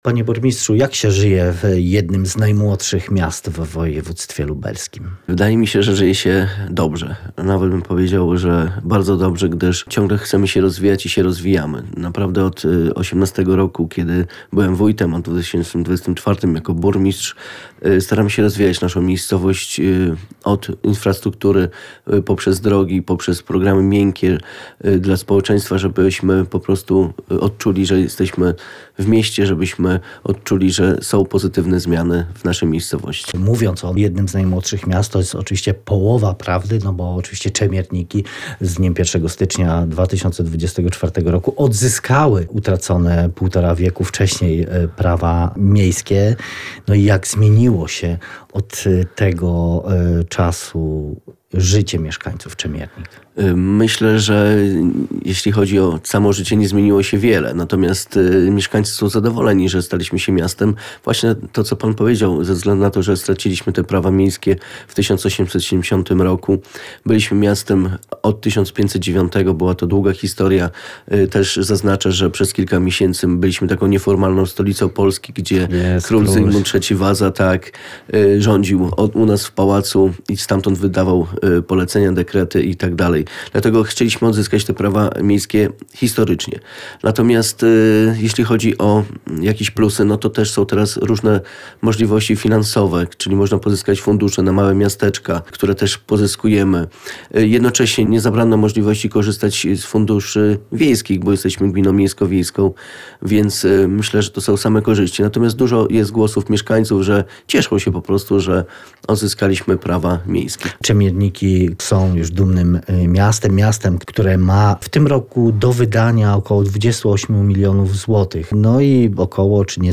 Czemierniki to jedno z najmłodszych miast w województwie lubelskim.